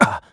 Riheet-Vox_Damage_kr_04.wav